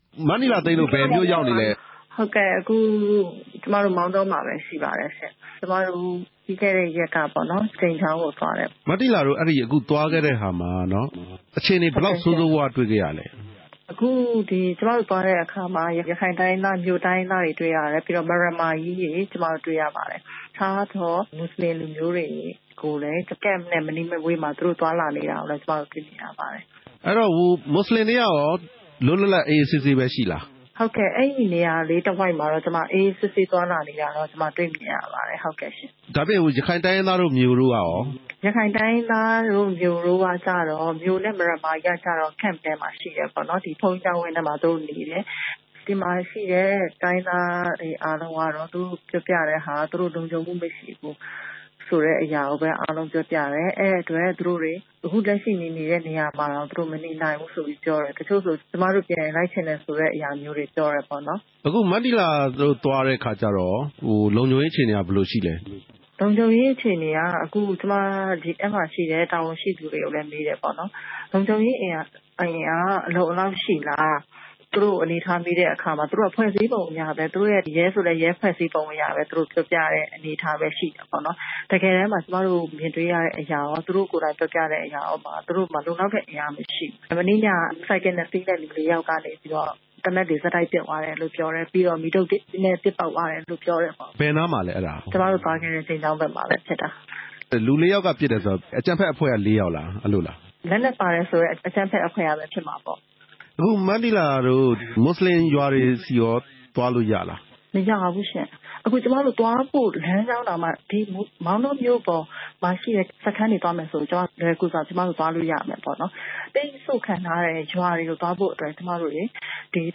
မောင်တောဒေသ အခြေအနေ မနီလာသိန်းနှင့် မေးမြန်းချက်